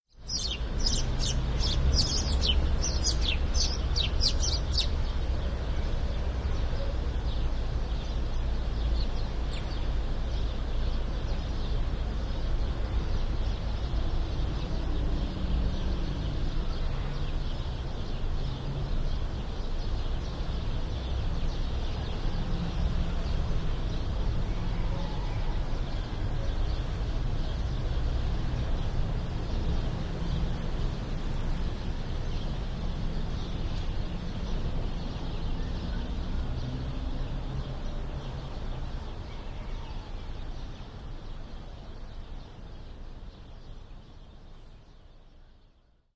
描述：鸟鸣在清晨，其中包括许多黑鸟。 1986年春末在德国西南部录制，带有录音机。
标签： 鸟鸣声 黎明 早晨 自然的声音 黎明合唱 性质 现场录音
声道立体声